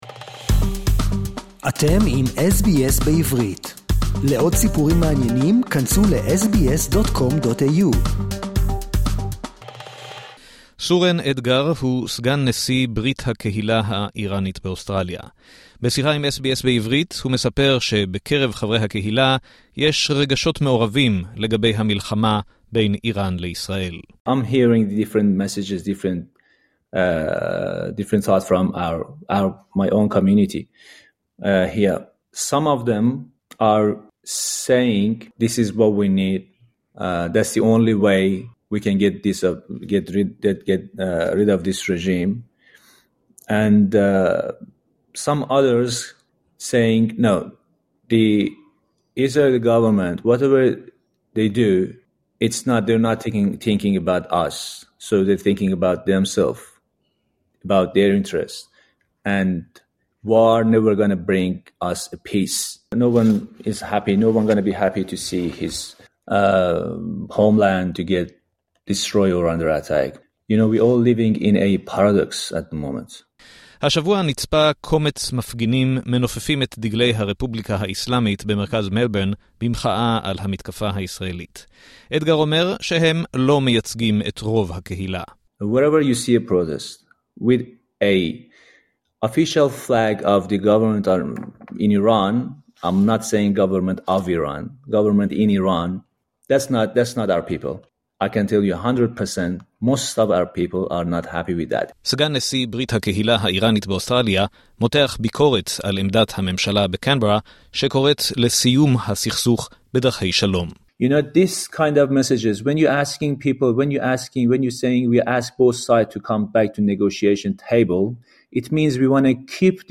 בצל המלחמה בין ישראל לאיראן, פנינו לשמוע מה חושבים יוצאי איראן שחיים כאן באוסטרליה. נציגים מהקהילה האיראנית משתפים בשיחה עם SBS Hebrew את תחושות החרדה והעצב שלהם, מביעים הקלה לנוכח חיסול בכירים במשמרות המהפכה, ומדגישים את הסולידריות שלהם עם אזרחי ישראל.